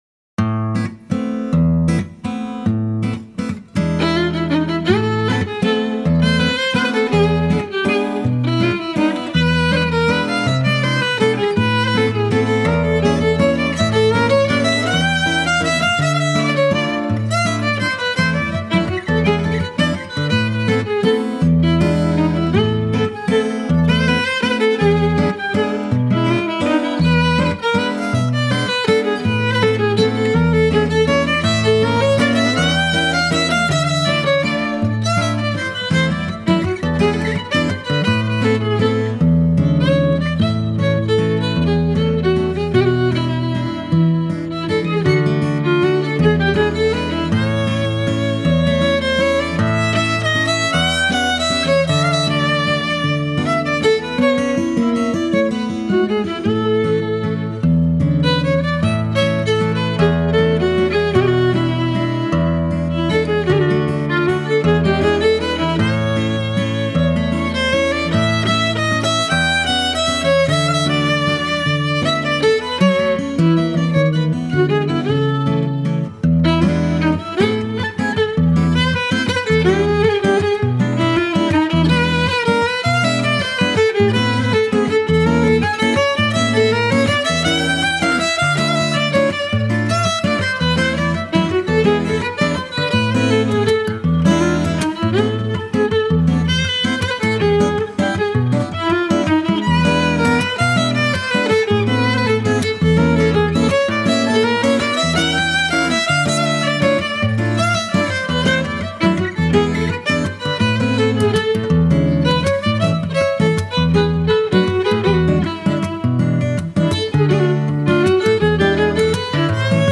fiddle
guitar
in an intimate duo recording